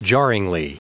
Prononciation du mot jarringly en anglais (fichier audio)